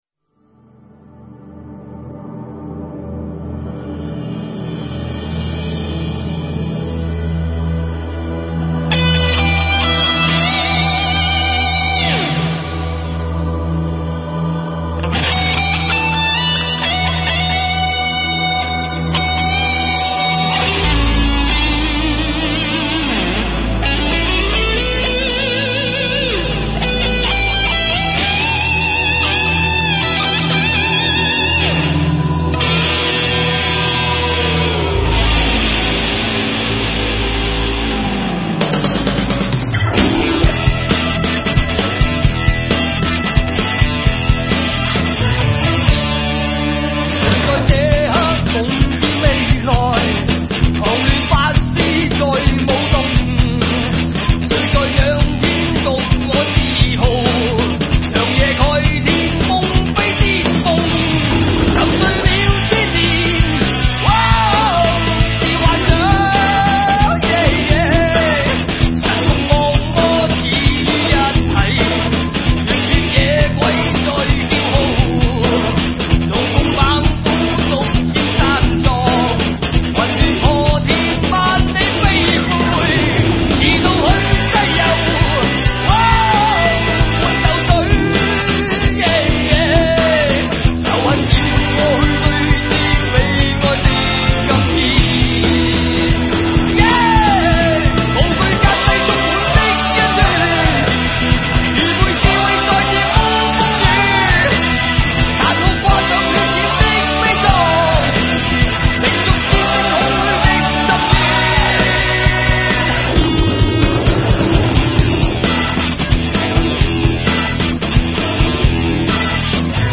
主唱